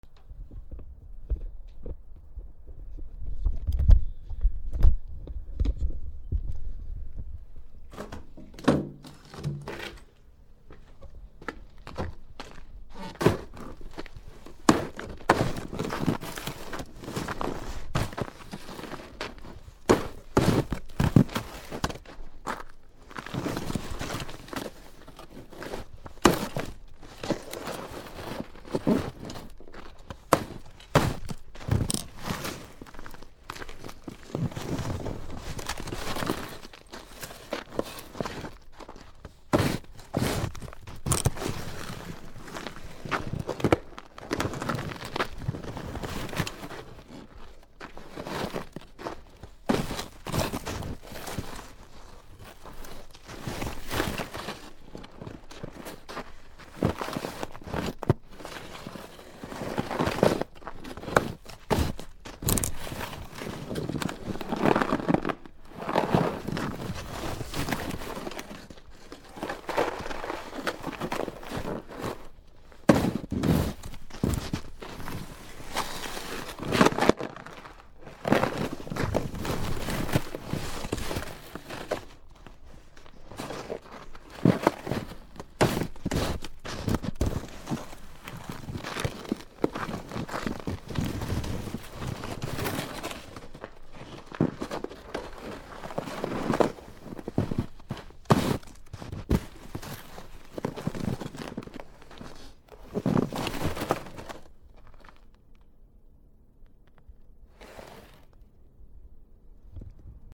/ M｜他分類 / L35 ｜雪・氷 /
スノーダンプ
MKH416